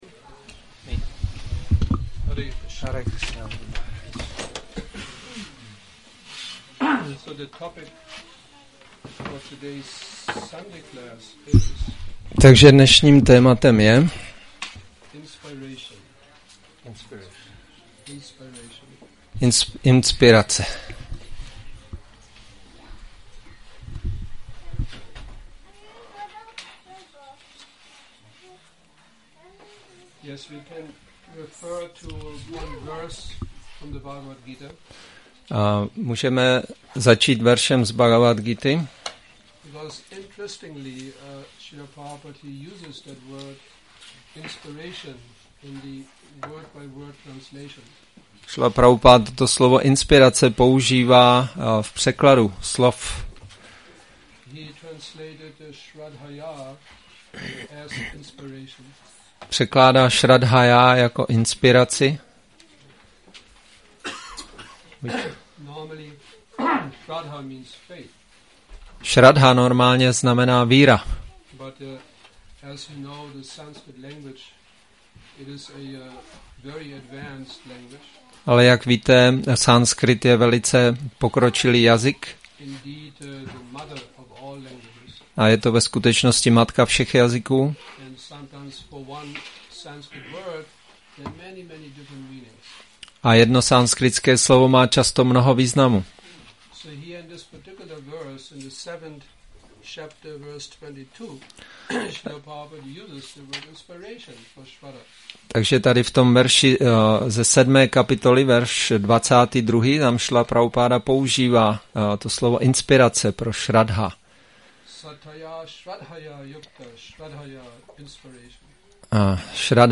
Přednáška Inspiration Nedělní program